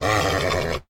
horse_angry.ogg